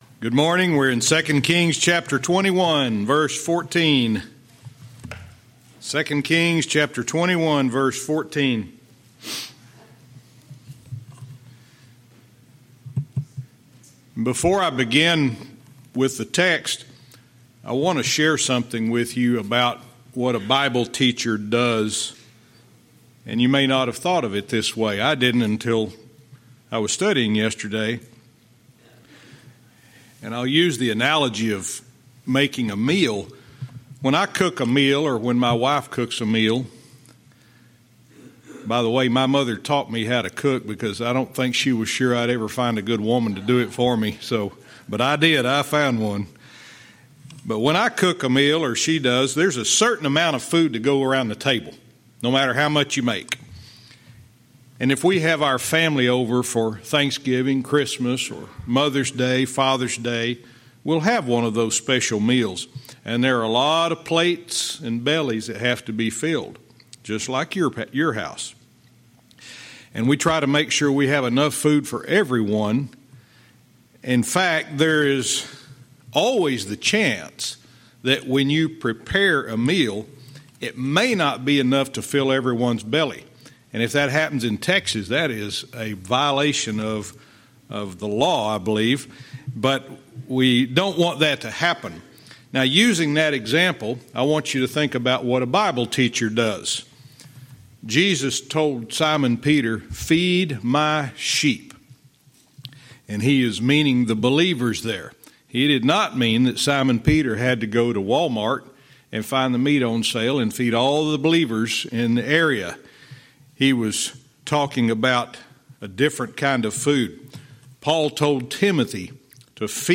Verse by verse teaching - 2 Kings 21:14-15